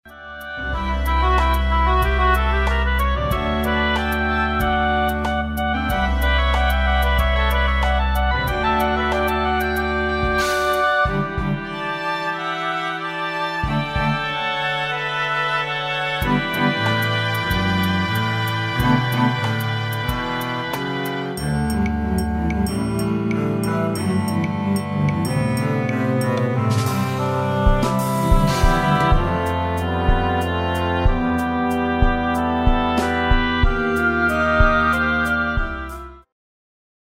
Музыка для театра